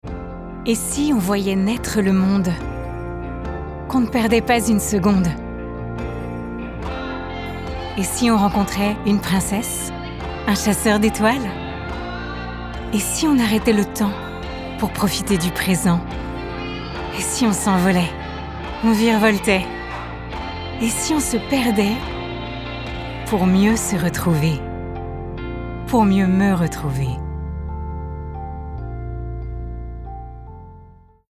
Voix off
pub Club Med